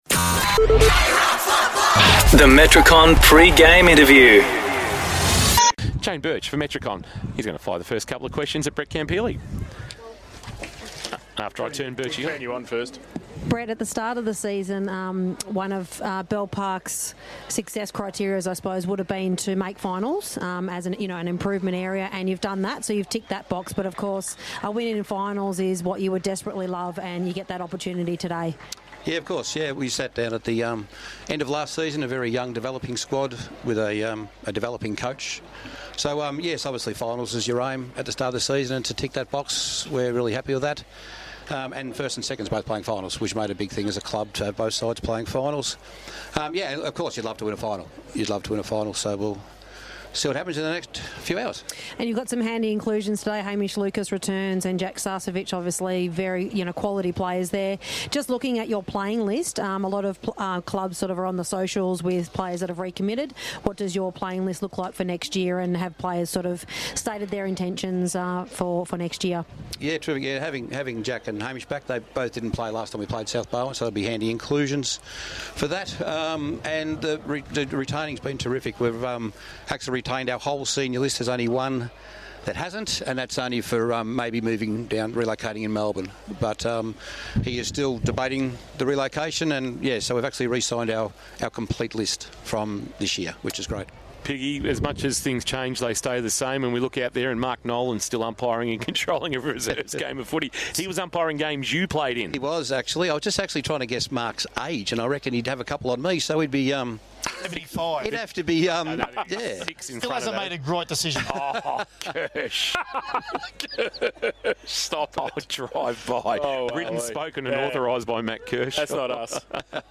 2024 - GFNL - Elimination Final - South Barwon vs. Bell Park: Pre-game interview